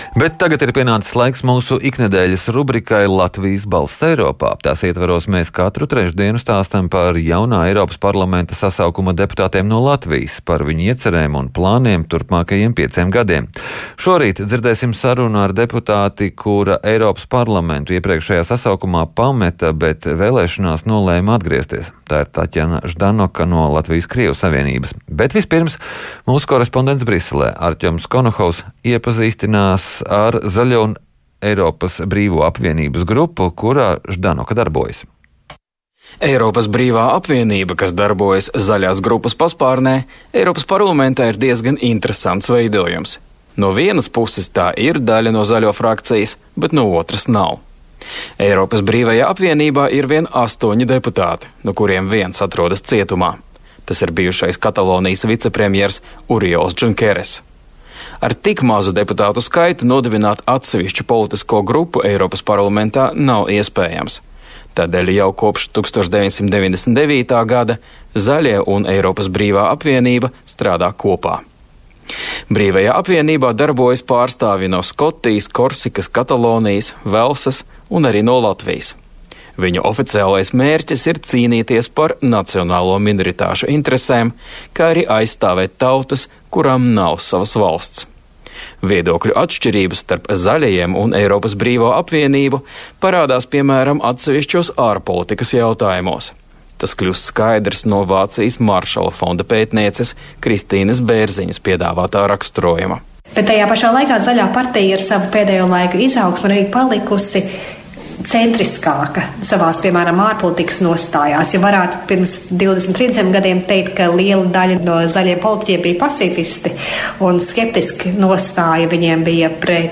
Latvijas balss Eiropas Parlamentā: Intervija ar Tatjanu Ždanoku
Turpinām iepazīstināt ar jaunā Eiropas Parlamenta sasaukuma deputātu iecerēm un iepazīstinām arī ar kādu ko Eiropas Parlamenta politiskajām grupām. Šorīt saruna ar deputāti, kura Eiropas Parlamentu iepriekšējā sasaukumā pameta, bet vēlēšanās nolēma atgriezties.